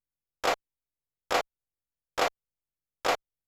LOFI CLP  -R.wav